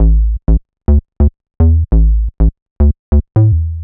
cch_bass_loop_rompler_125_Em.wav